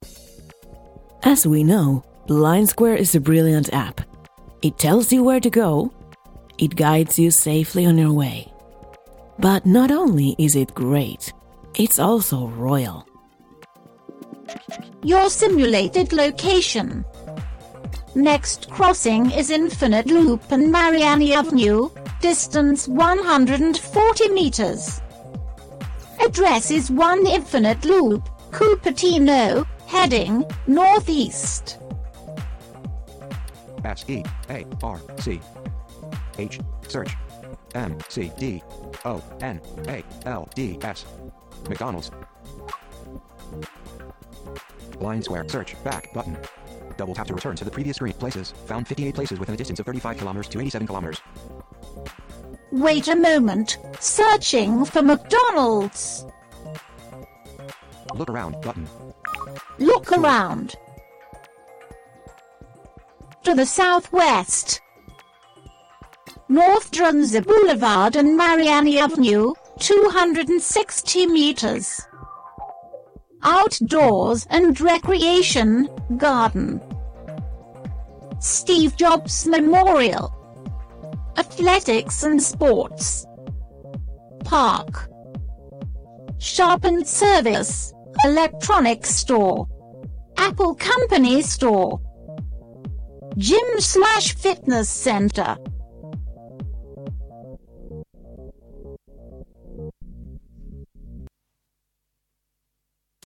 Today we get to hear the voice of really royal Blindsquare!